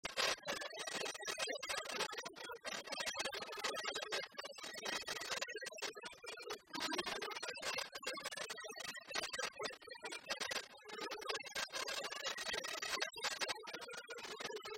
Chants brefs - A applaudir Résumé : Si t'étais venu, t'aurais manger de l'andouille, comme t'es pas venu, elle est restée pendue.
Pièce musicale inédite